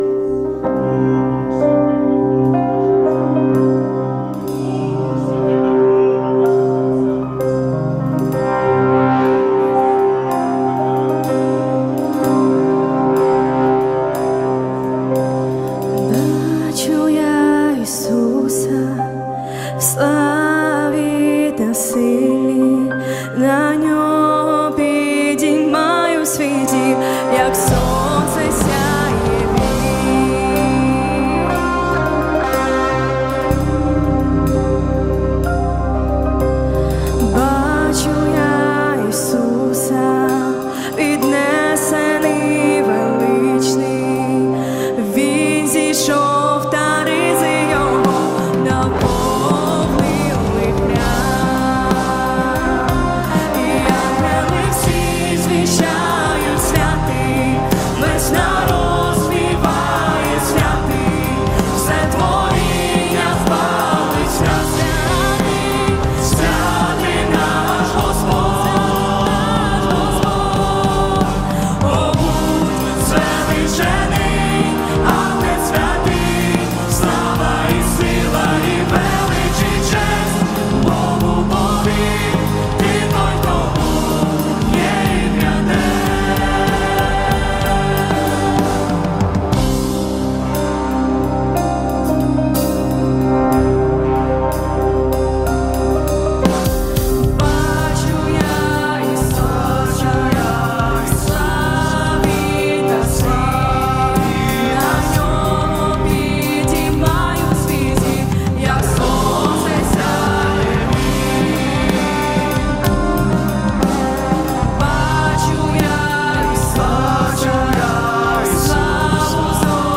461 просмотр 911 прослушиваний 49 скачиваний BPM: 185